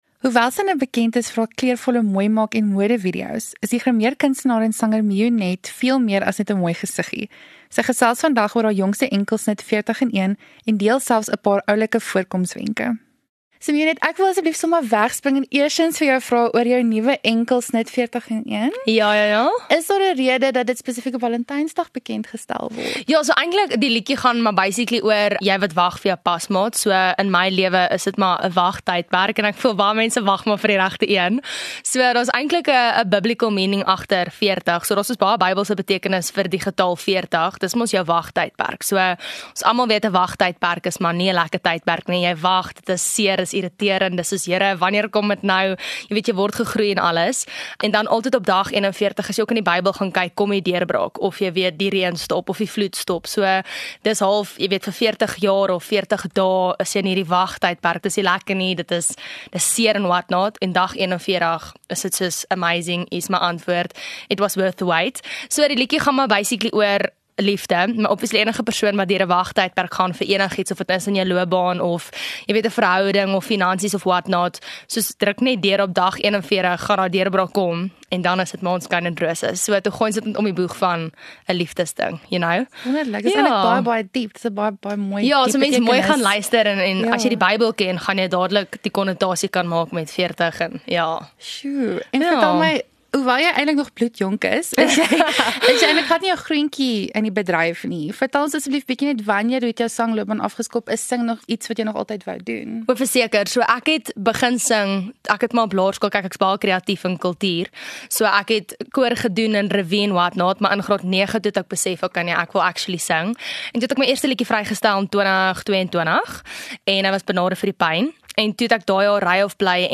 Maroela Media gesels met interessante mense in die ateljee.